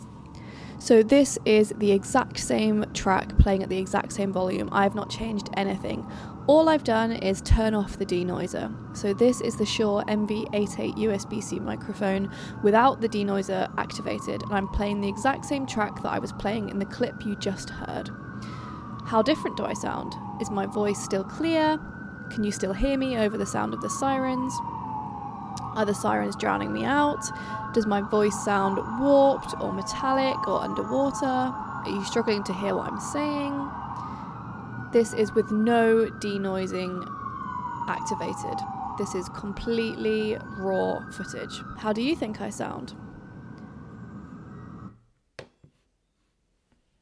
Here’s the same clip without the Denoiser activated.
As you can hear, the sirens and traffic noise are much, much louder without the Denoiser. There are a few moments where my voice grows a little grating, and it sounds like the ambient noise might drown me out.
denoiser off.mp3